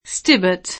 [ingl. S t & bët ] cogn. — con pn. italianizz. [ S t & bbert ]